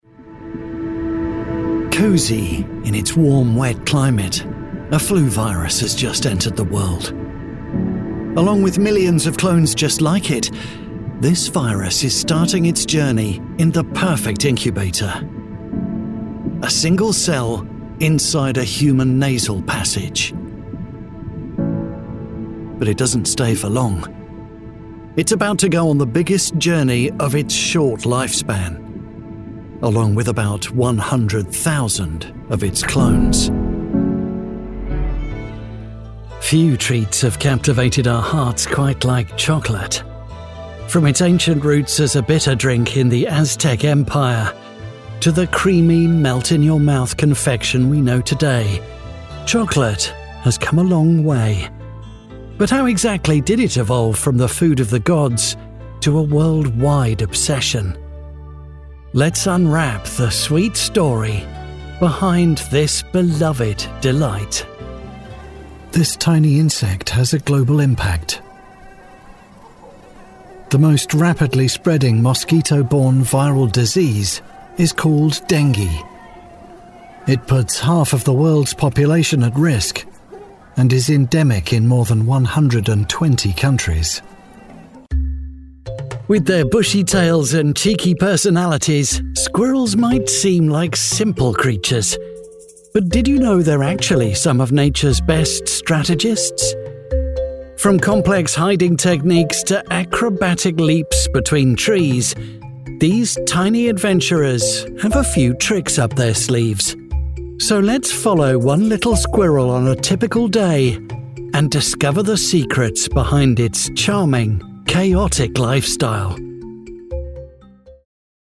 Documentaries
Experienced, conversational voice actor with warmth and integrity.
Professional Sound Booth